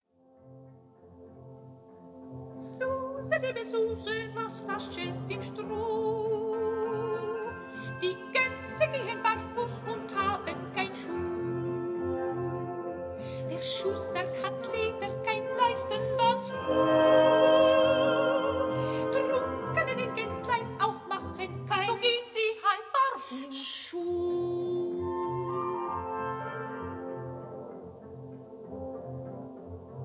includes Dance Duet